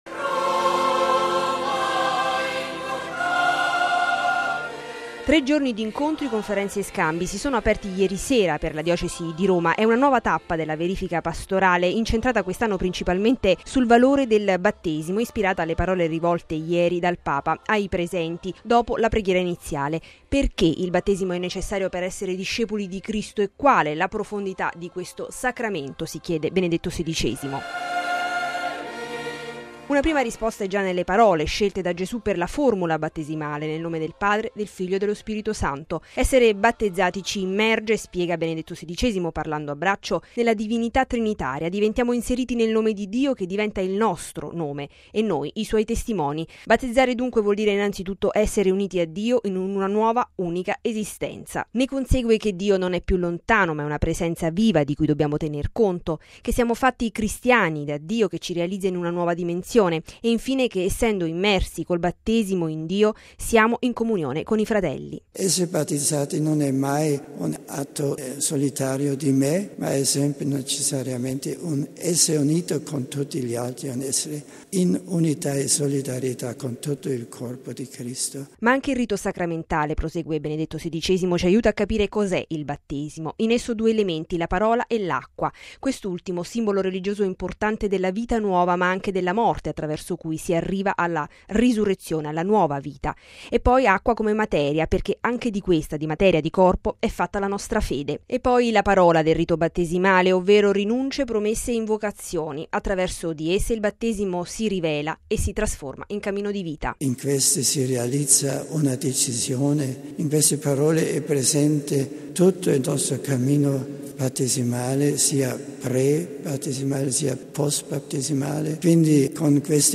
Essere battezzati ci immerge - spiega Benedetto XVI, parlando a braccio - nella divinità trinitaria; diventiamo inseriti nel nome di Dio, che diventa il nostro nome e noi i suoi testimoni.